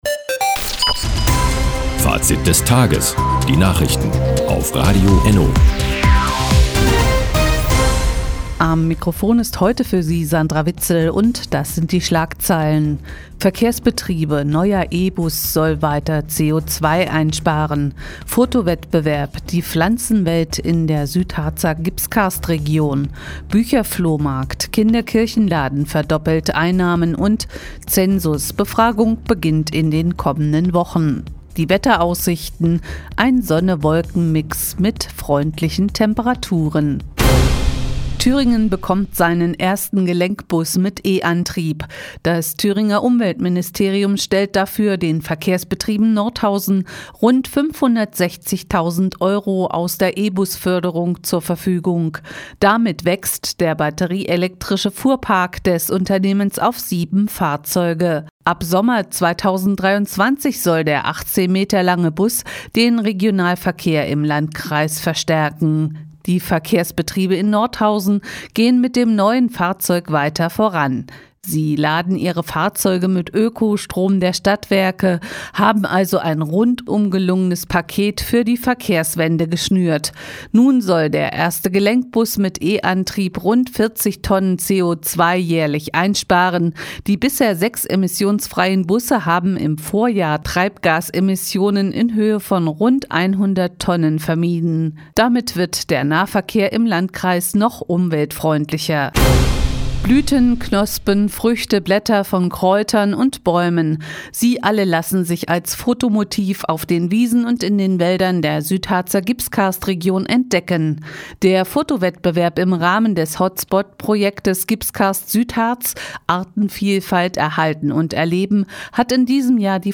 Mo, 16:58 Uhr 02.05.2022 Neues von Radio ENNO Fazit des Tages Seit Jahren kooperieren die Nordthüringer Online-Zeitungen und das Nordhäuser Bürgerradio ENNO. Die tägliche Nachrichtensendung ist jetzt hier zu hören...